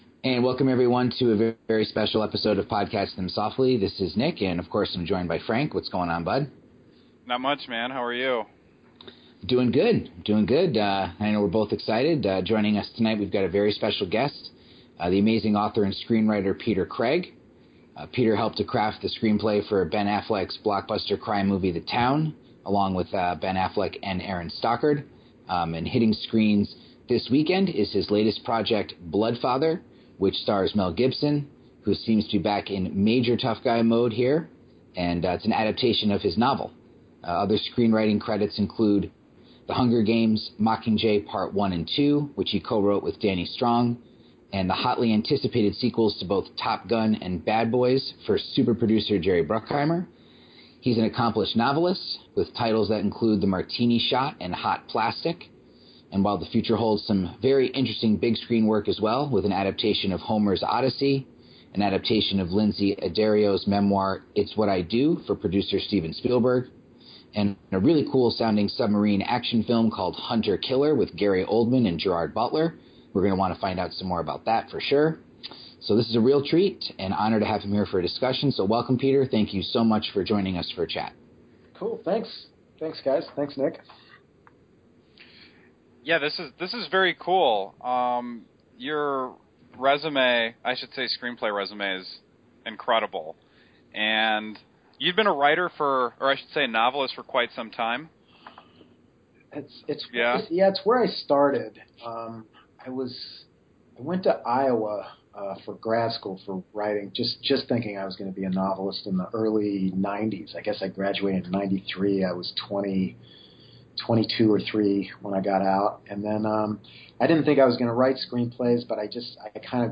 Podcasting Them Softly is thrilled to present an exciting chat with the extremely talented author and screenwriter